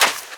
STEPS Sand, Run 15.wav